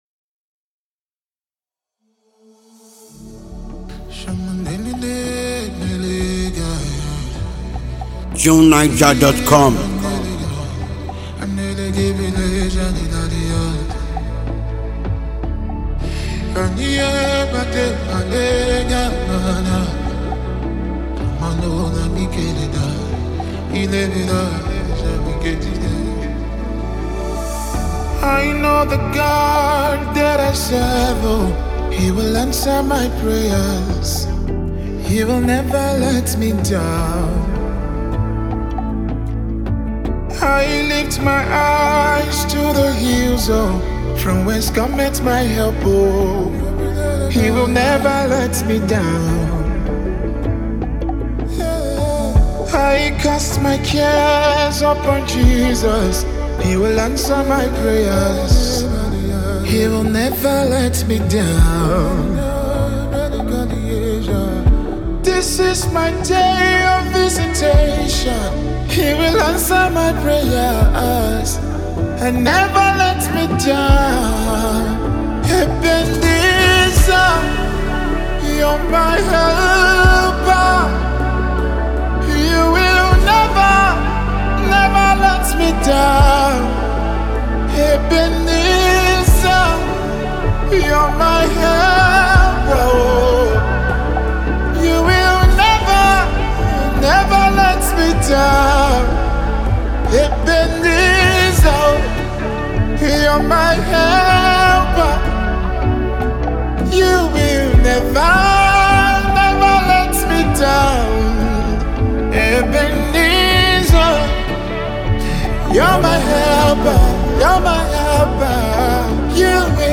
a phenomenally talented Nigerian gospel recording artist
heart-wrenching song